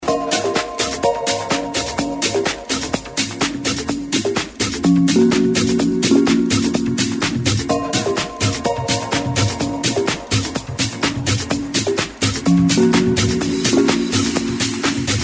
Help ID this awesome prog house track
This track was played by many DJs.